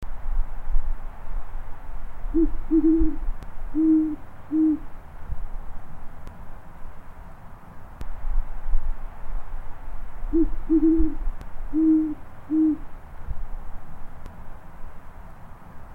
Great Horned Owl